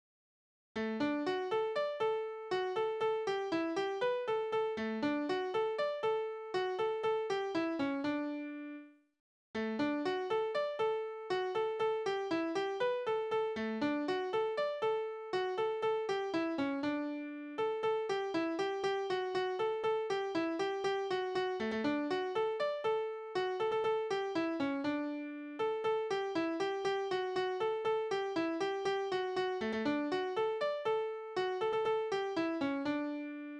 Spielverse:
Tonart: D-Dur
Taktart: 2/4
Tonumfang: Oktave, Quarte
Besetzung: vokal